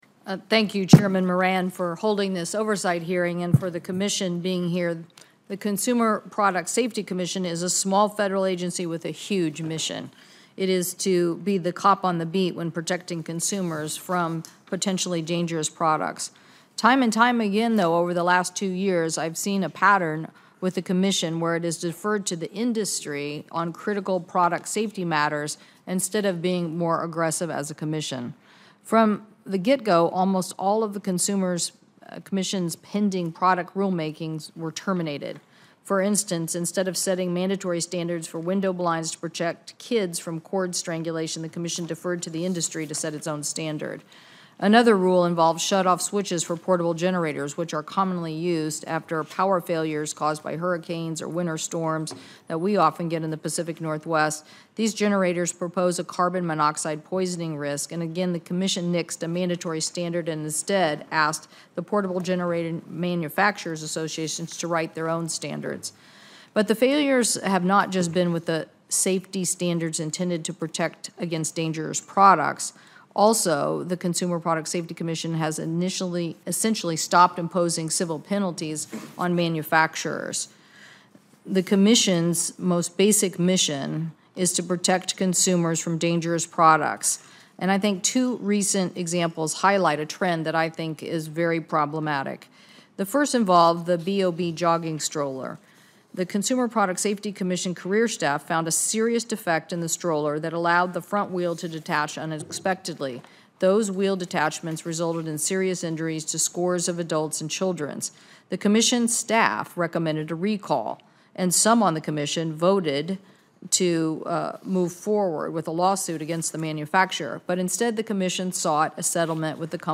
commerce-cpsc-opening-statement-audio&download=1